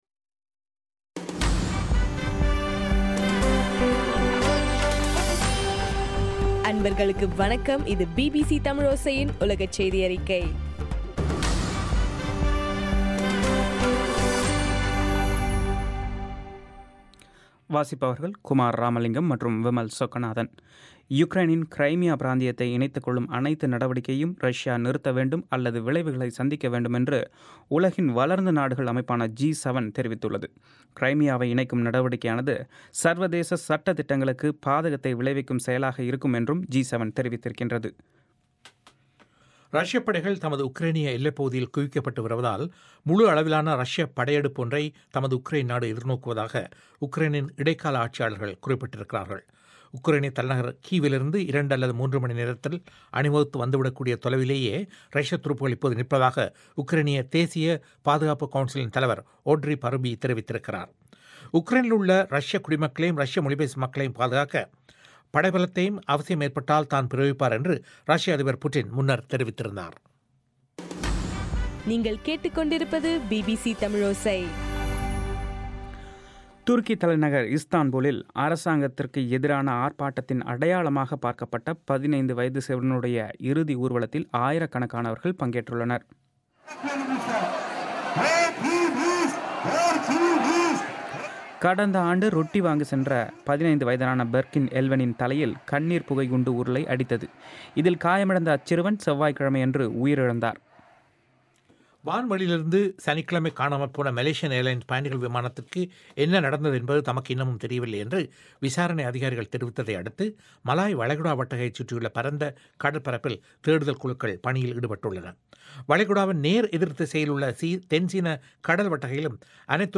மார்ச் 12 - பிபிசி உலகச் செய்திகள்